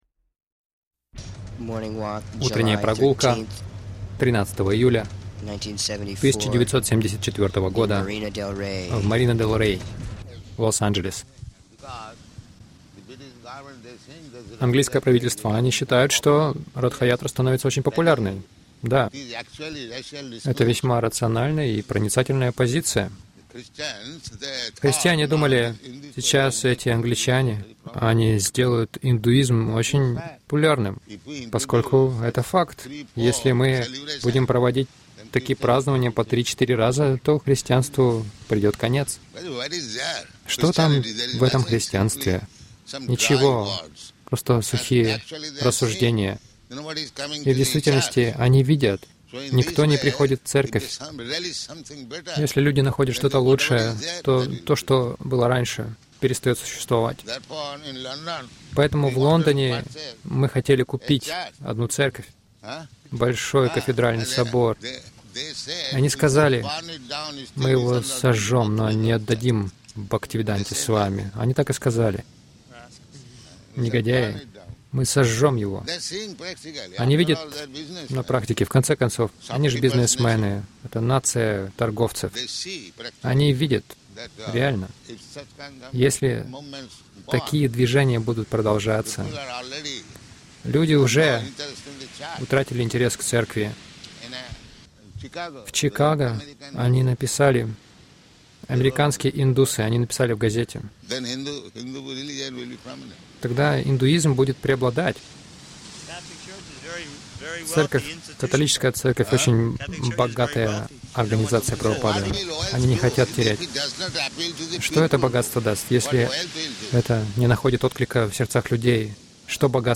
Милость Прабхупады Аудиолекции и книги 13.07.1974 Утренние Прогулки | Лос-Анджелес Утренние прогулки — Заговор против Ратха ятры Загрузка...